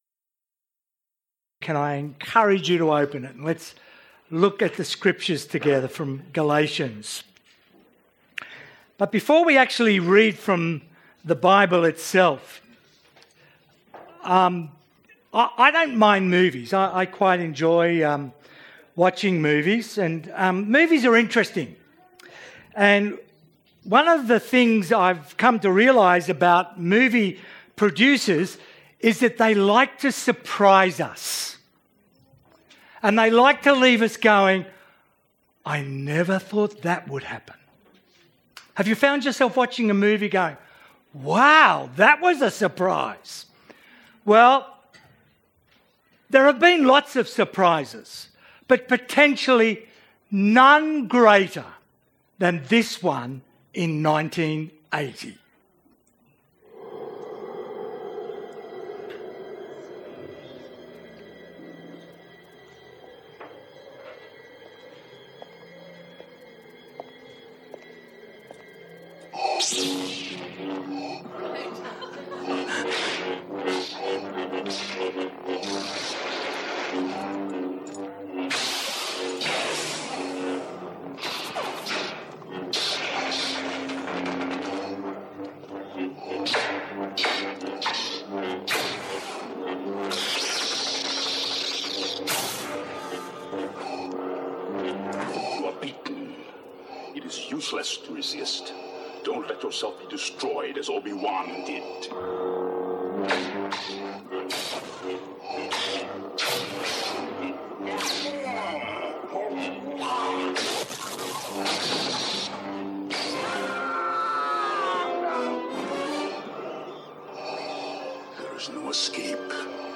Categories Sermon Tags 2016